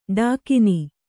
♪ ḍākini